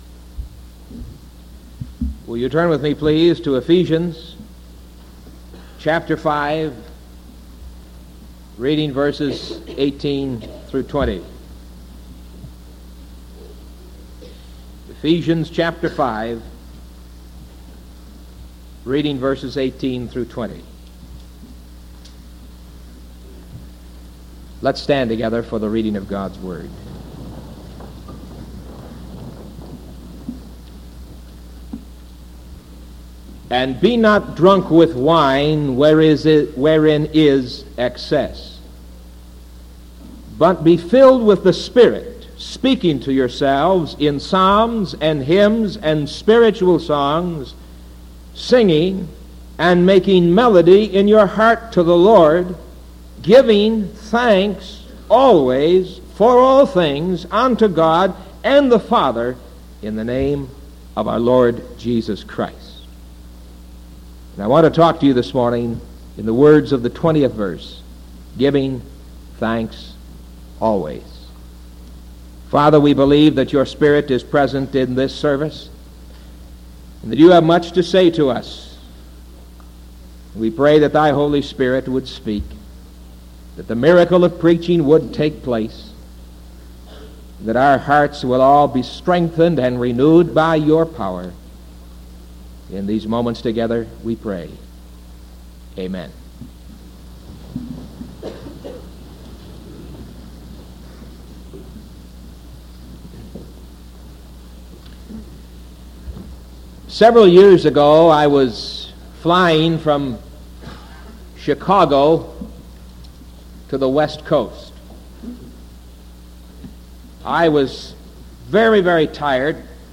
Sermon November 18th 1973 AM